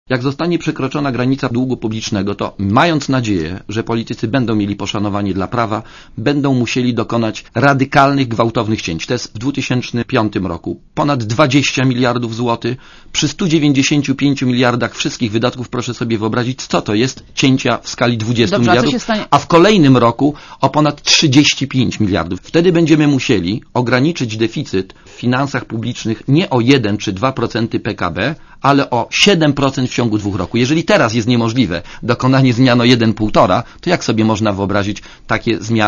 To najgorszy budżet od co najmniej 10 lat - mówi Bogusław Grabowski, członek Rady Polityki Pieniężnej, dzisiejszy gość Radia Zet.